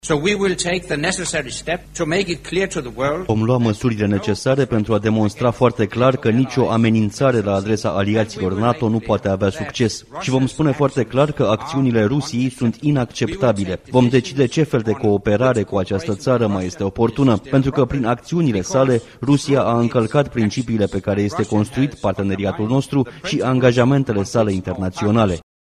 Secretarul-general al Alianţei Nord-Atlantice, Anders Fogh Rasmussen, afirmă că nu are dovezi conform cărora trupele ruse s-ar fi retras de la graniţa cu Ucraina. El a declarat, înaintea reuniunii de la Bruxelles a miniştrilor de externe NATO, că stabilitatea europeană este ameninţată de comportamentul Rusiei: